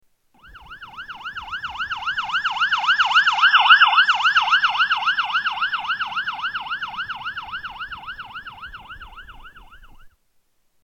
Ambulance Siren Pass By